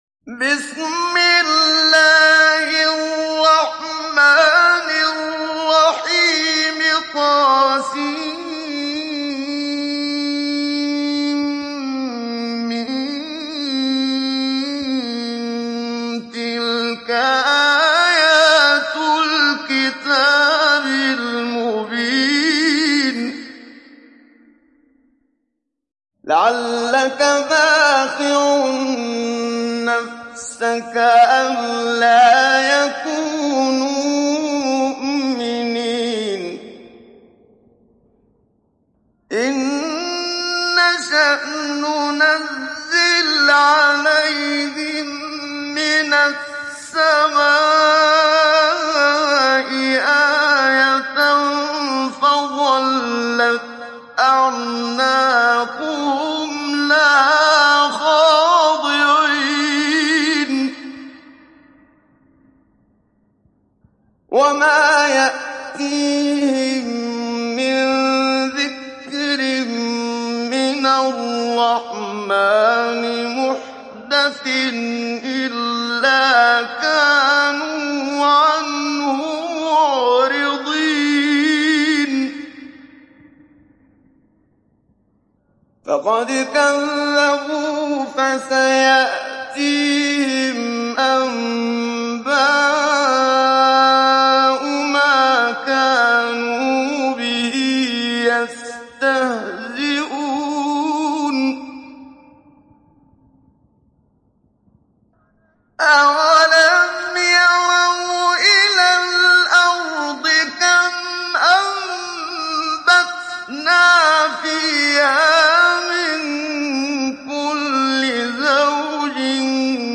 دانلود سوره الشعراء mp3 محمد صديق المنشاوي مجود روایت حفص از عاصم, قرآن را دانلود کنید و گوش کن mp3 ، لینک مستقیم کامل
دانلود سوره الشعراء محمد صديق المنشاوي مجود